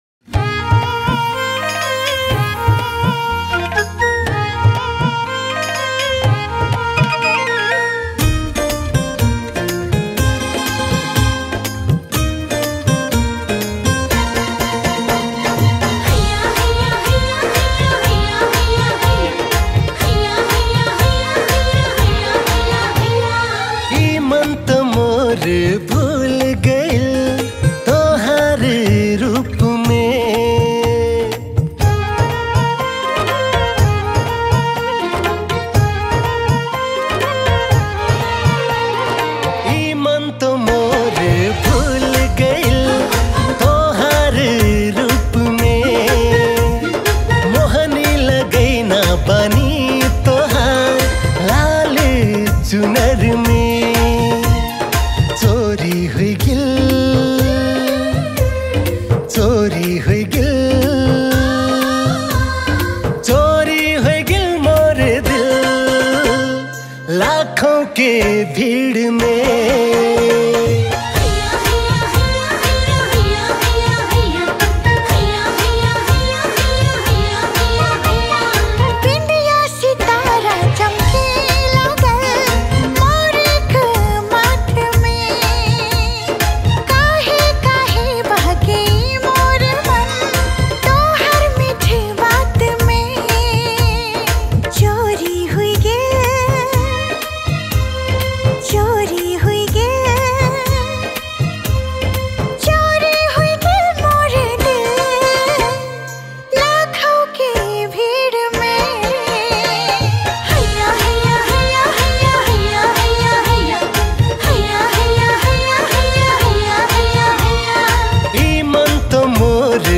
Tharu Romantic Song